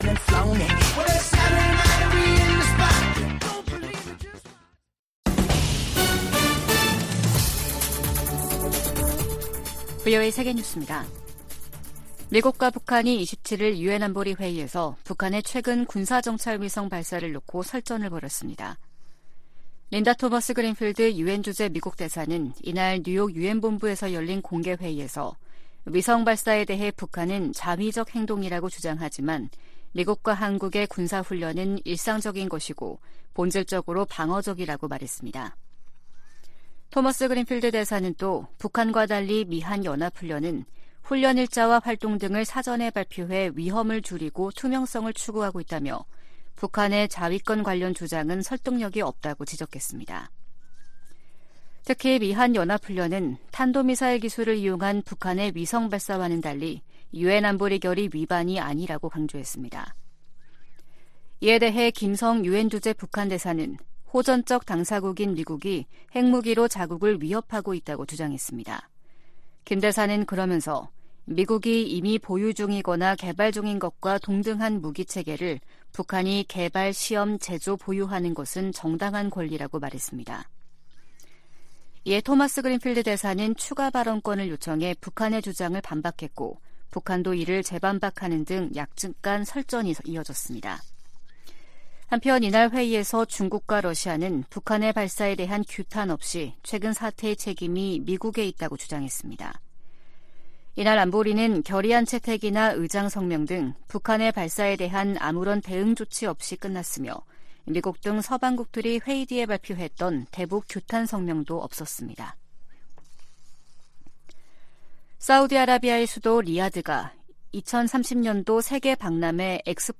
VOA 한국어 아침 뉴스 프로그램 '워싱턴 뉴스 광장' 2023년 11월 29일 방송입니다. 북한은 군사정찰위성 ‘만리경 1호’가 백악관과 군 기지 등 미국 본토 내 주요 시설을 촬영했다고 주장했습니다.